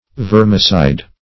Vermicide \Ver"mi*cide\, n. [L. vermis a worm + caedere to